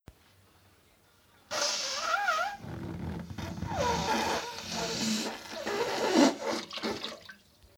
Globo desinflándose
Grabación sonora del sonido producido por un globo desinflándose. Se escuchan pedorretas producidas por la salida del aire por un orificio pequeño e irregular